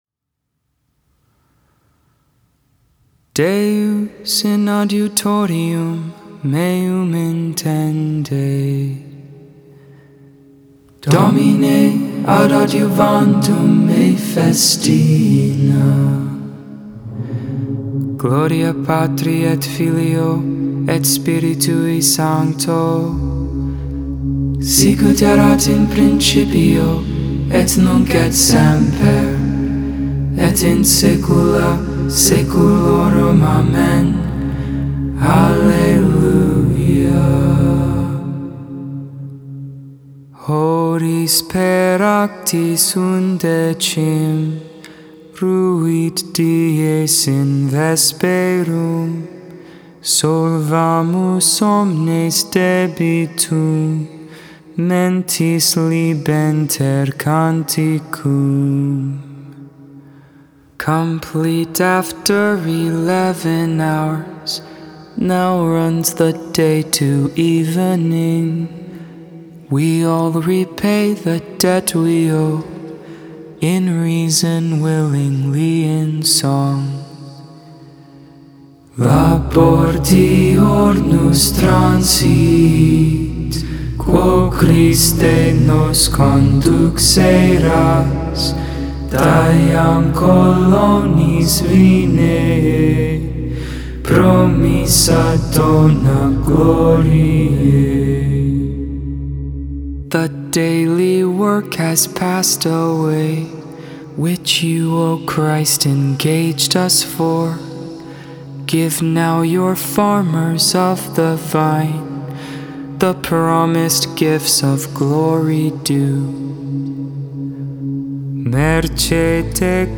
Mozarbic Hymn
(STH tone)
(tone 1)
The Lord's Prayer Concluding Prayers Salve Regina (Gregorian) The Liturgy of the Hours (Four Volu